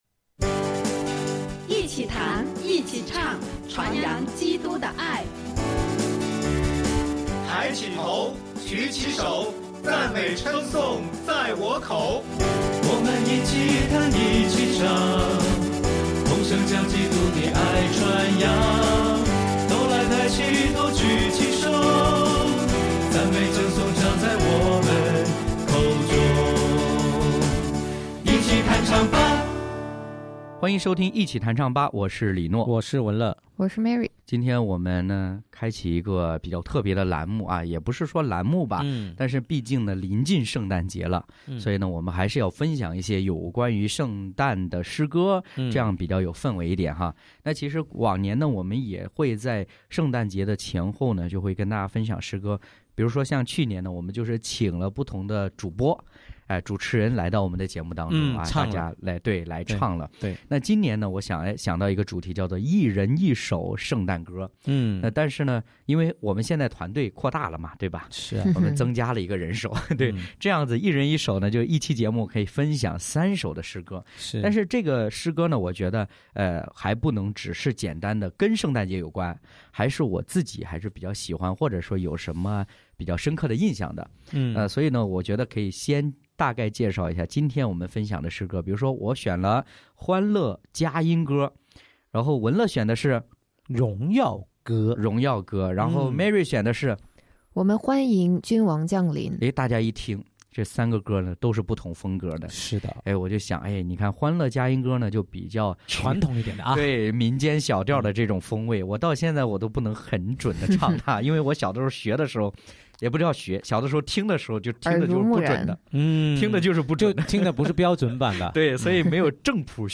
一人一首圣诞歌：《欢乐圣诞佳音》、《荣耀歌》、《我们欢迎君王降临》